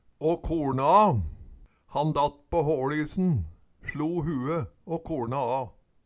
Høyr på uttala Ordklasse: Uttrykk Kategori: Uttrykk Attende til søk